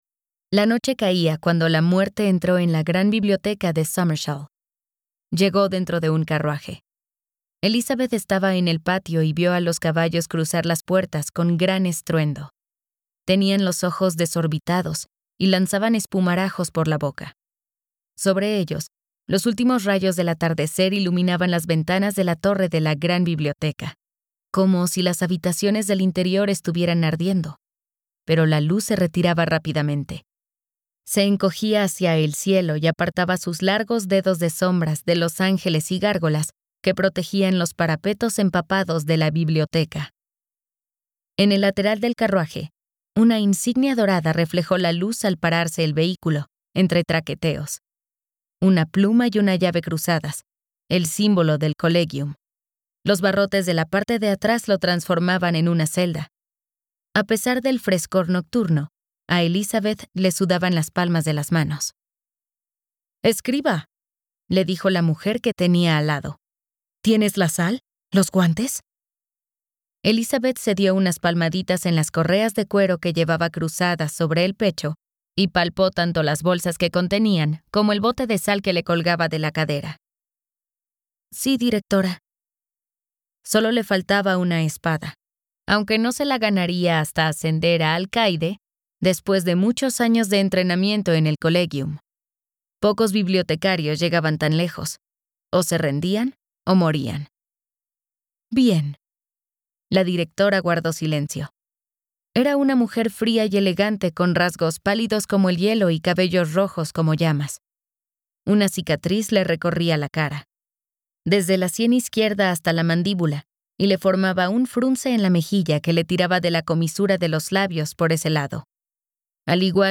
Audiolibro Embrujo de espinas (Sorcery of Thorns)